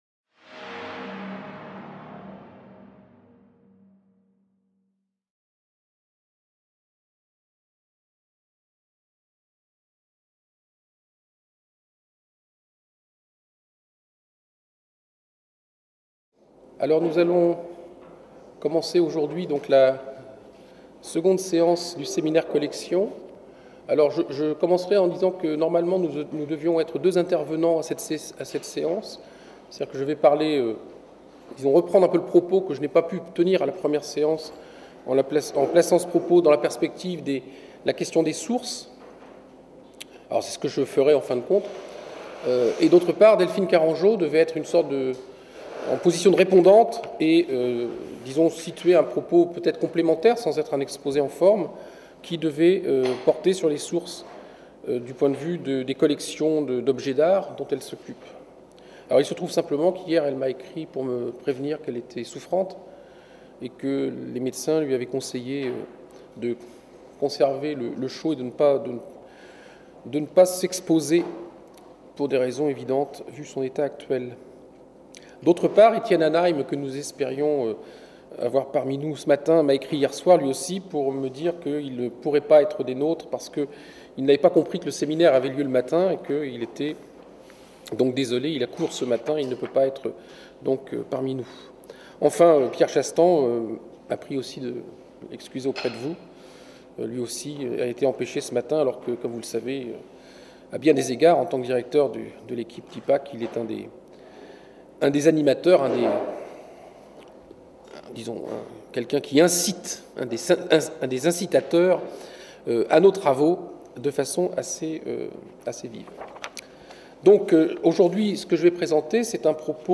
Seconde séance du séminaire "Collections" 2014-2015.